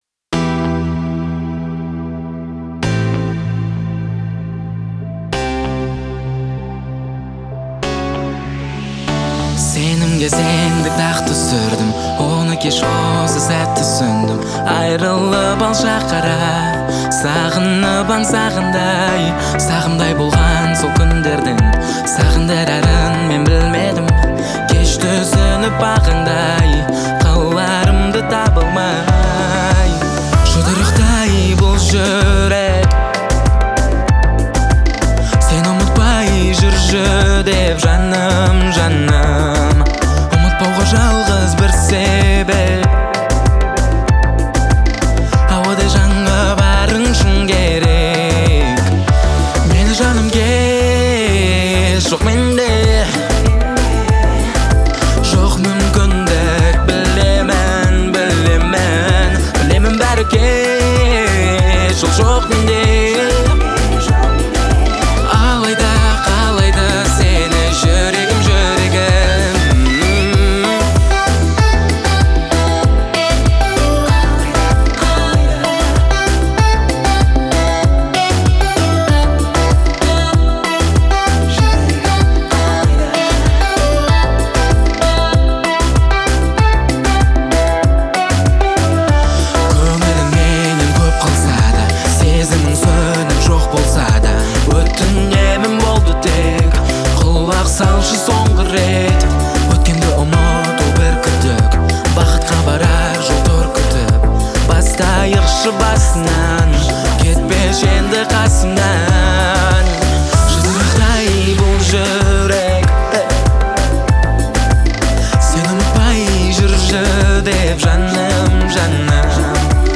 казахской поп-музыки